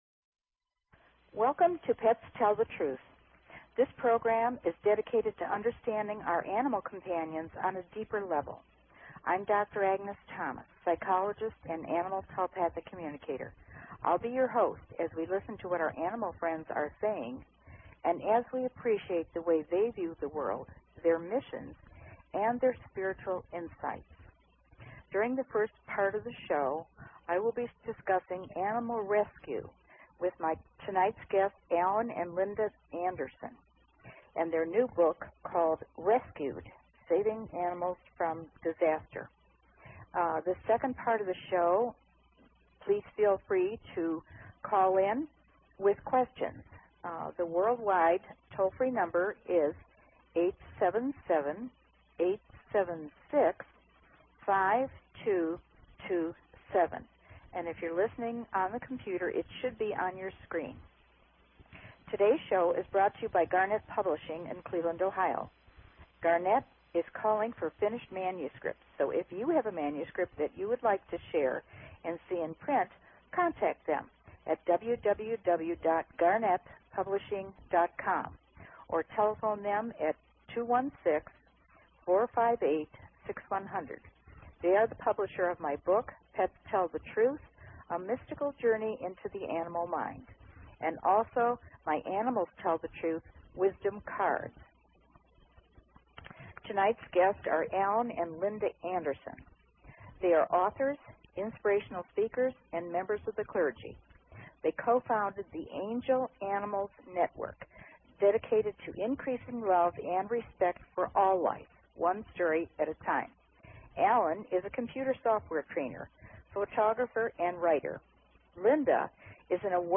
Talk Show Episode, Audio Podcast, Pets_Tell_The_Truth and Courtesy of BBS Radio on , show guests , about , categorized as